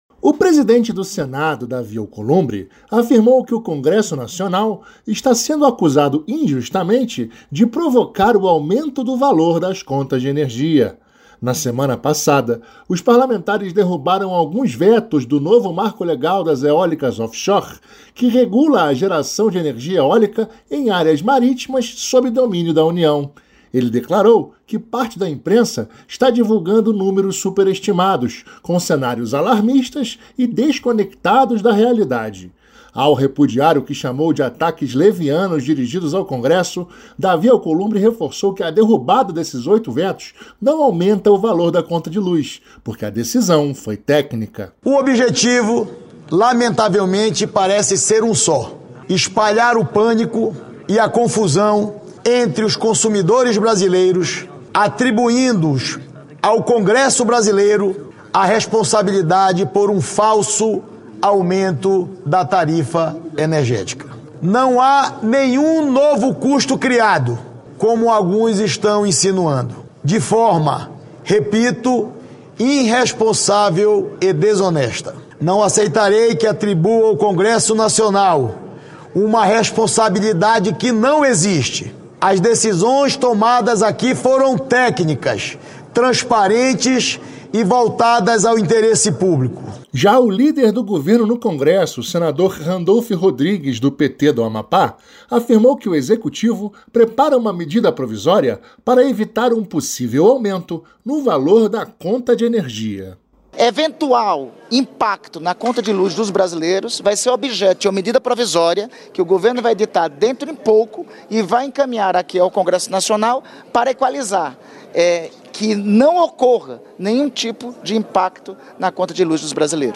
Senador Davi Alcolumbre
Senador Randolfe Rodrigues